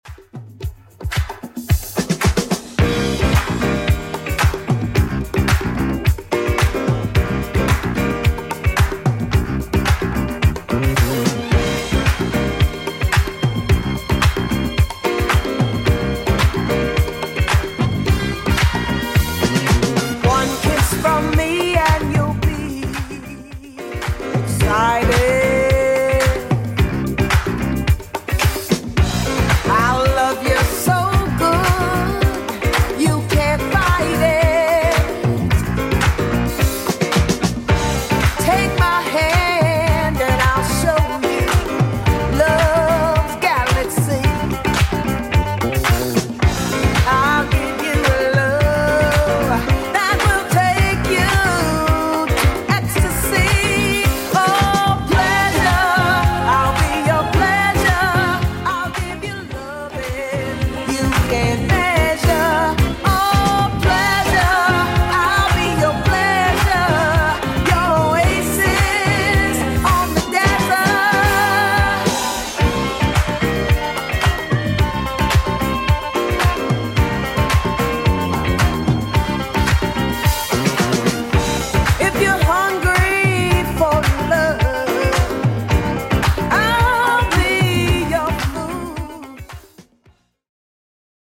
Genre: 90's
BPM: 98